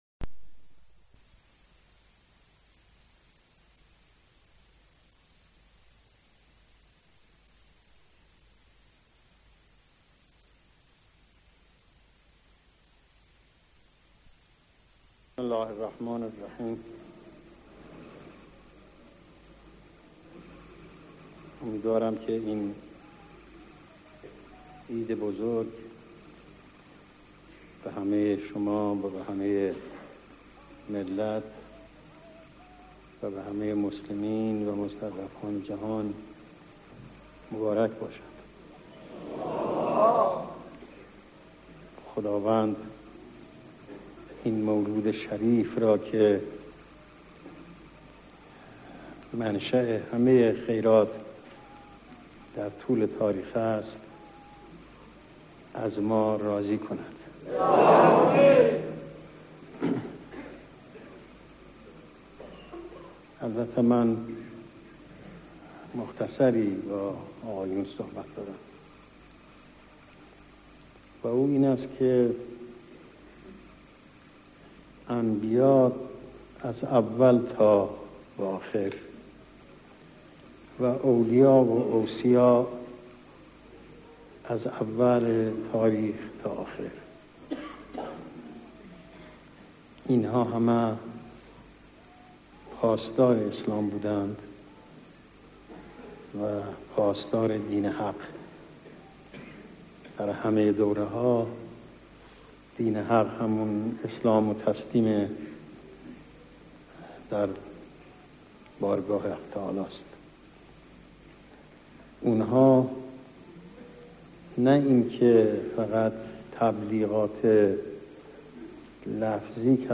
پاسدارى از دین حق تبیین عوامل شکست و پیروزى پاسدارى از دین حق تبیین عوامل شکست و پیروزى ۰:۰۰ ۰:۰۰ دانلود صوت کیفیت بالا عنوان : پاسدارى از دین حق تبیین عوامل شکست و پیروزى مکان : تهران، حسینیه جماران تاریخ : ۱۳۶۴-۰۲-۰۴